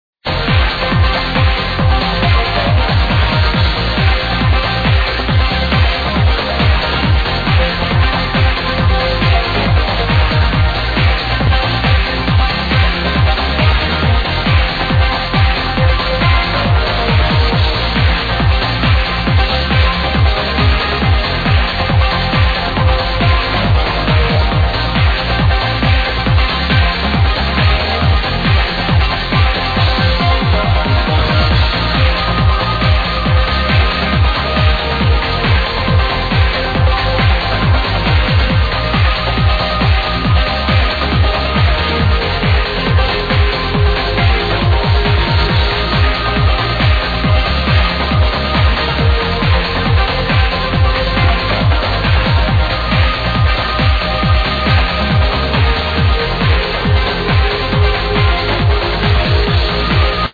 Beautiful tune from 2001 in need of iding
Please help id this melodic/banging trance tune from 2001.